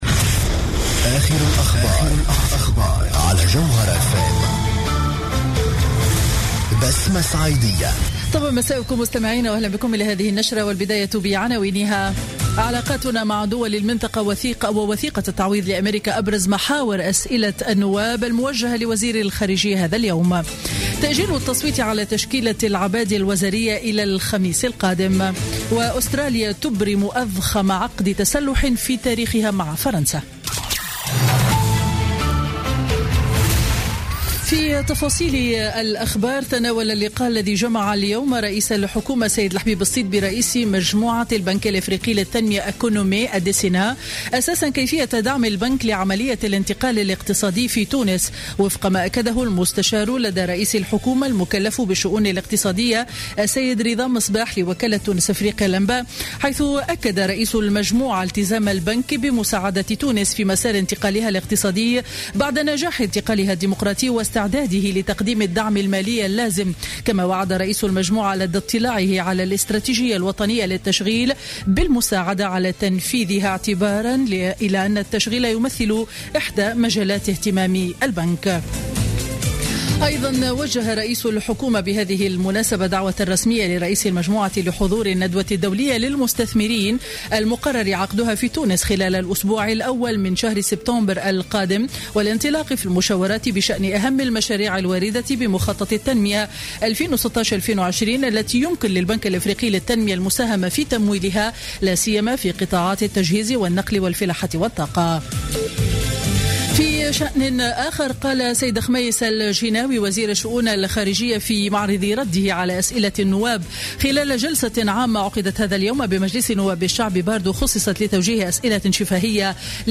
نشرة أخبار السابعة مساء ليوم الثلاثاء 26 أفريل 2016